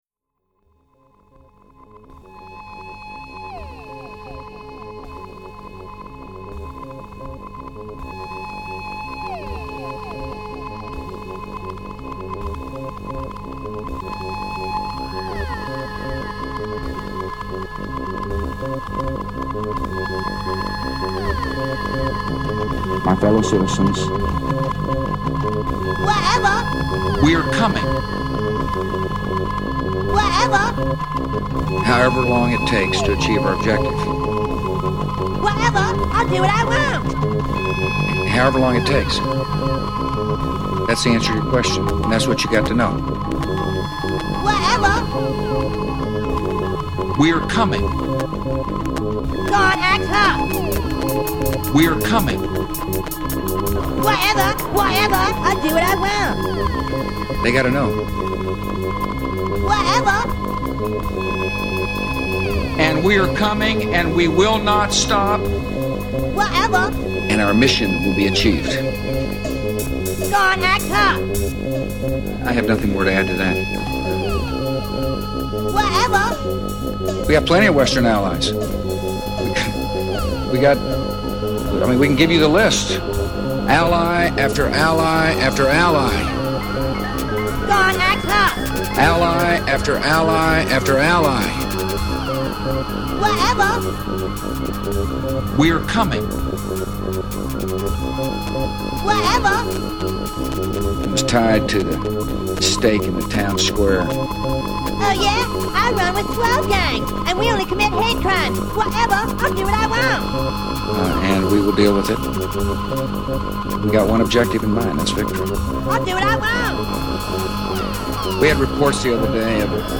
heres three pieces of cut up bush gibbering put to music.
bush gibbering put to music